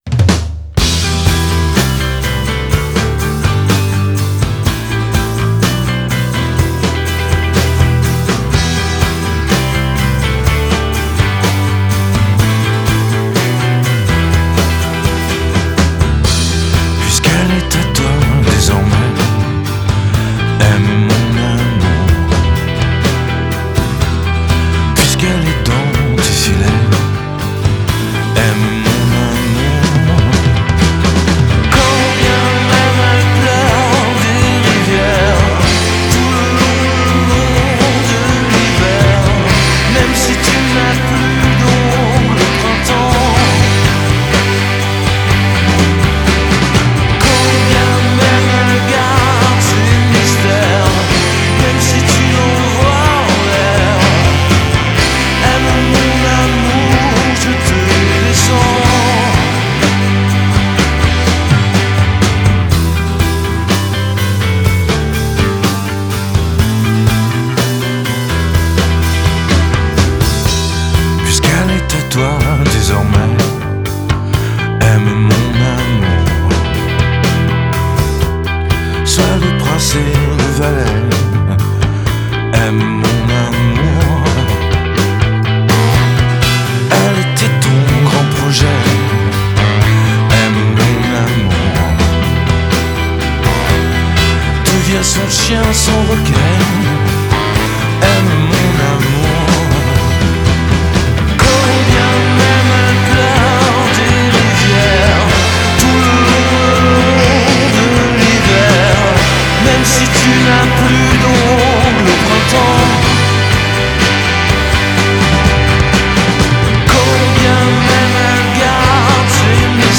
Genre: Pop, Chanson, French